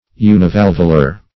\U`ni*val"vu*lar\